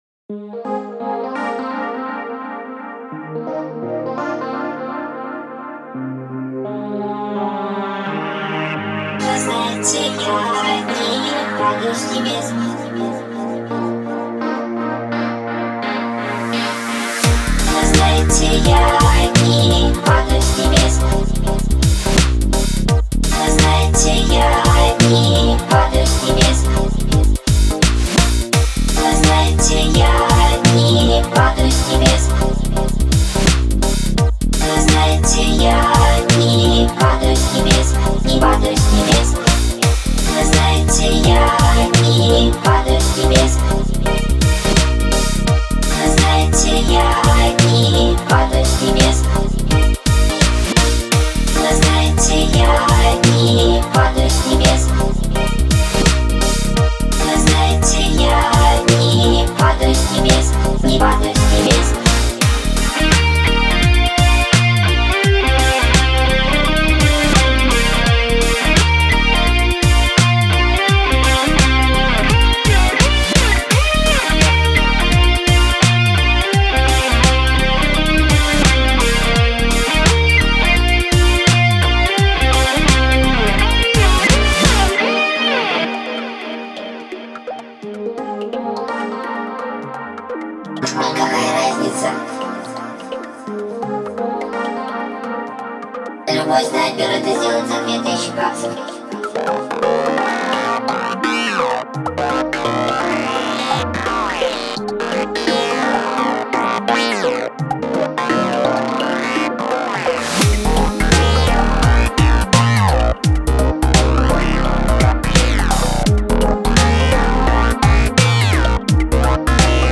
Мемовый Psy Dub